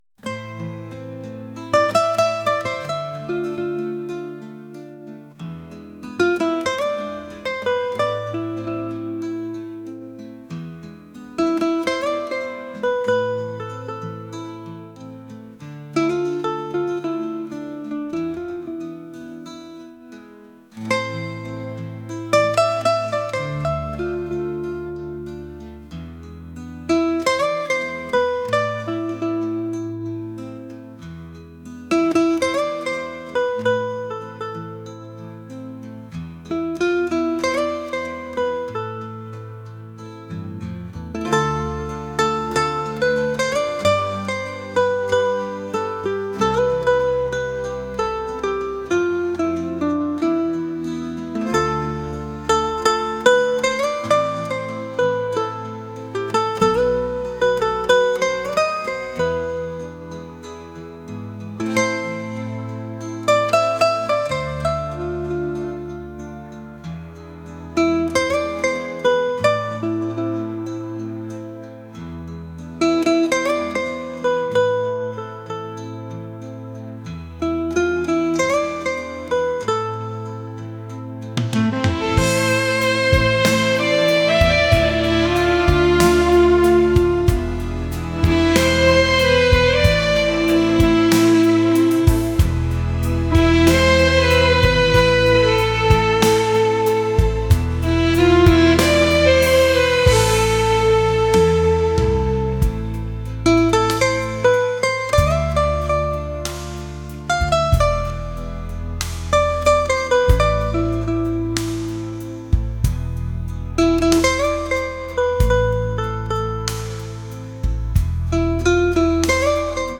pop | soul & rnb | acoustic